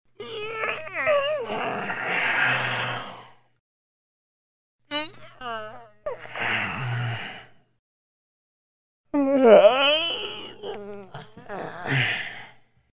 На этой странице собраны натуральные звуки зевания и потягиваний — от утренних до вечерних.
Зевота с потягиванием парня